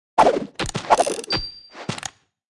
Media:Sfx_Anim_Ultimate_Colt.wav 动作音效 anim 在广场点击初级、经典、高手、顶尖和终极形态或者查看其技能时触发动作的音效
Sfx_Anim_Super_Colt.wav